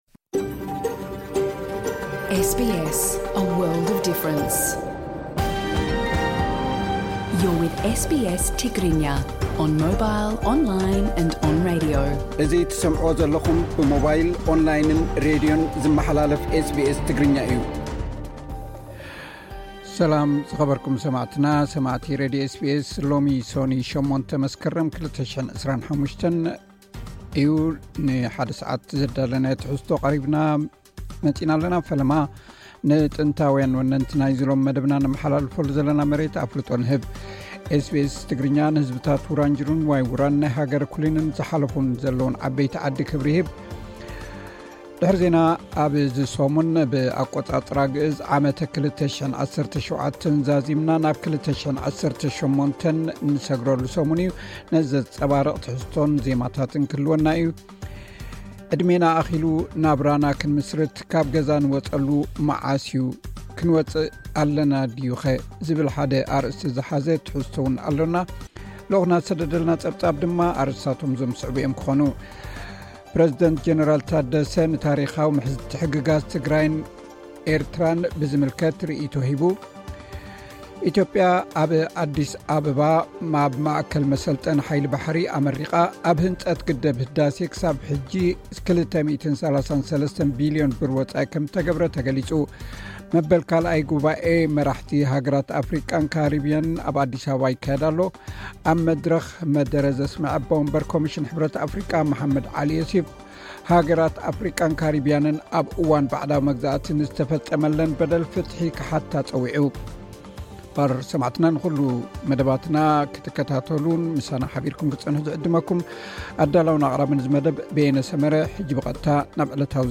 ዕለታዊ ዜና ኤስ ቢ ኤስ ትግርኛ (8 መስከረም 2025)